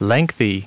Prononciation du mot lengthy en anglais (fichier audio)
Prononciation du mot : lengthy